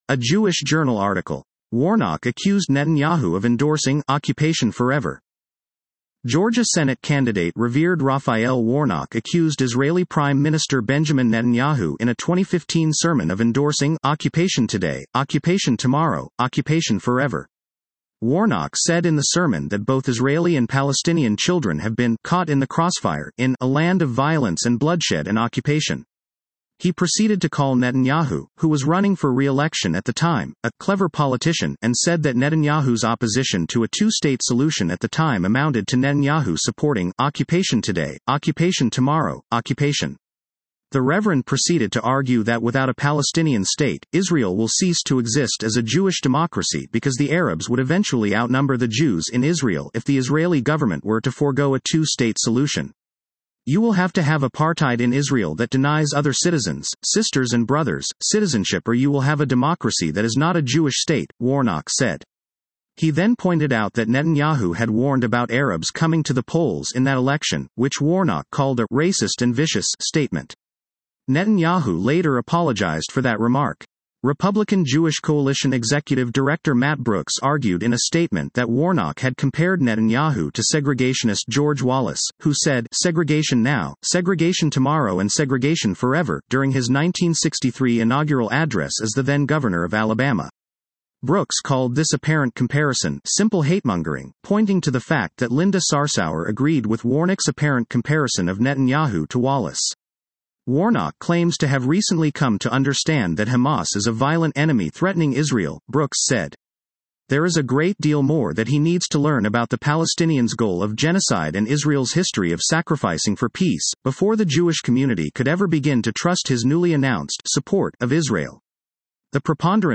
Georgia Senate candidate Revered Raphael Warnock accused Israeli Prime Minister Benjamin Netanyahu in a 2015 sermon of endorsing “occupation today, occupation tomorrow, occupation forever.”